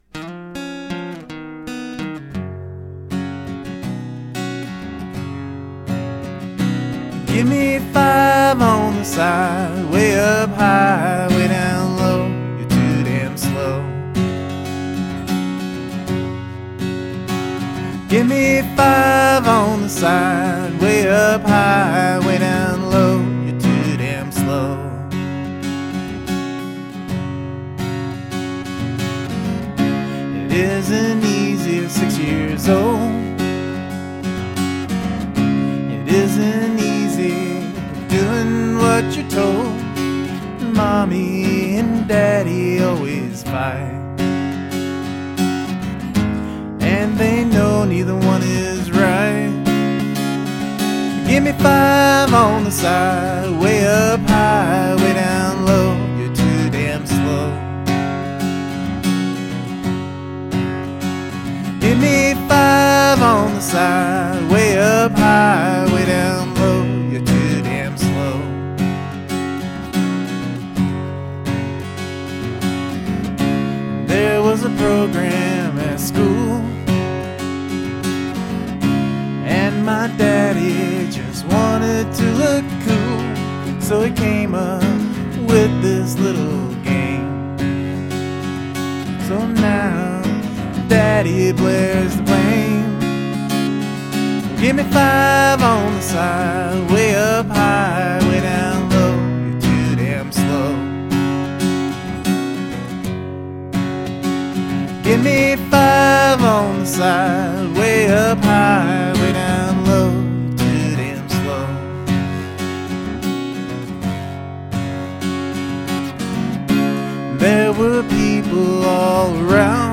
The gimme five part is a great melody.
The tune itself is great and works OK acoustically, but I can hear a bass and some drum work in there with maybe a background vocal chorus.